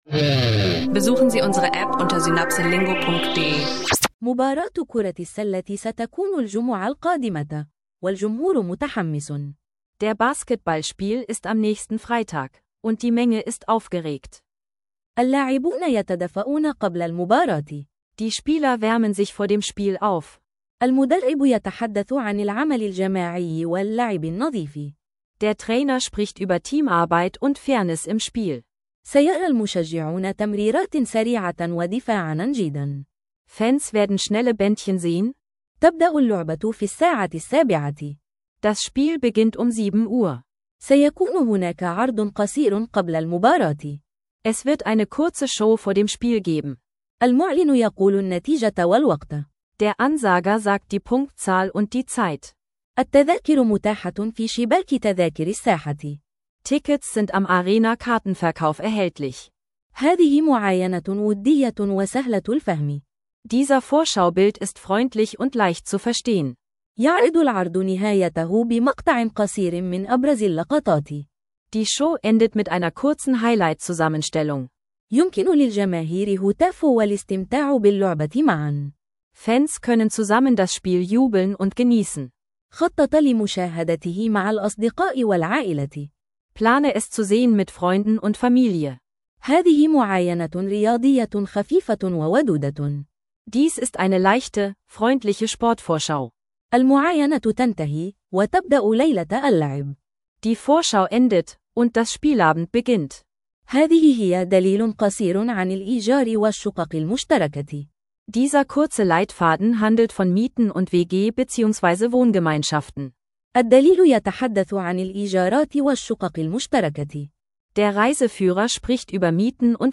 Ein kurzer, freundlicher Sprachkurs über Sportvorschau, Mietrecht und gemeinsames Wohnen – perfekt für Arabisch-Anfänger